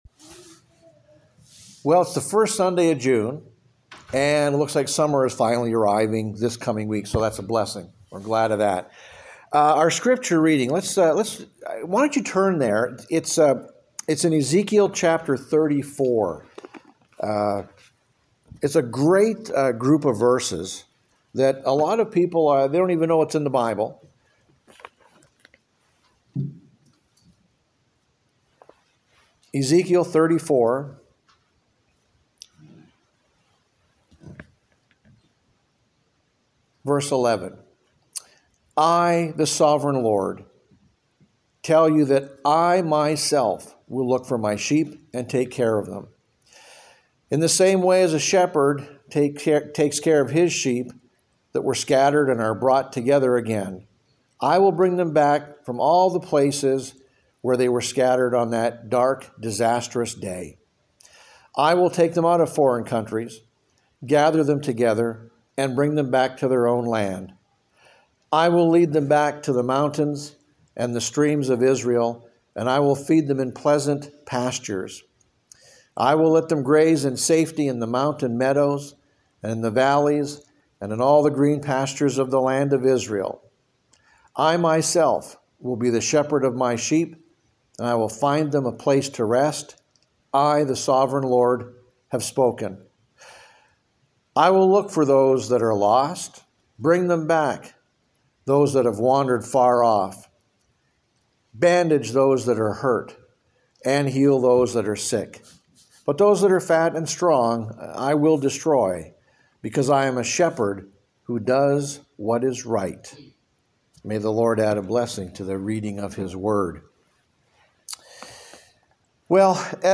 Published June 2, 2025 Uncategorized Leave a Comment PANIC PODCAST SUMMER SERMON SERIES: HOPE What?